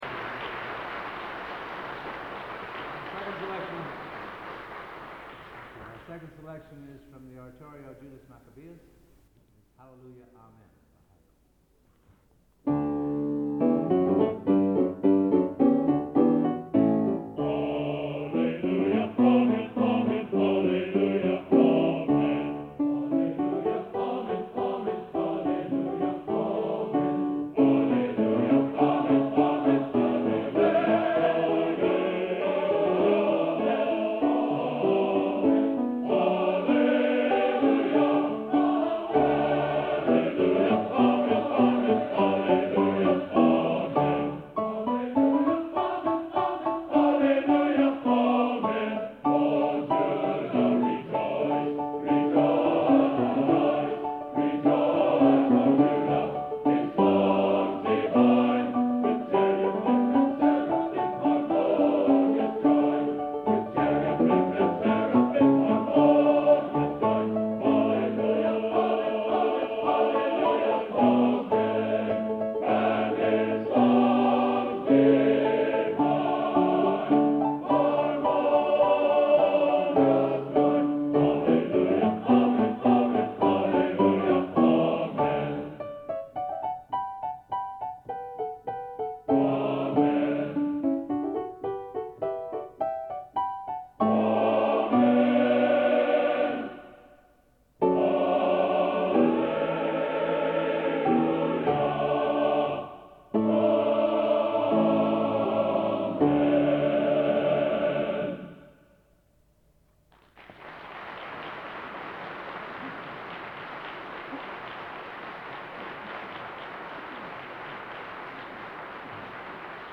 Location: Plymouth, England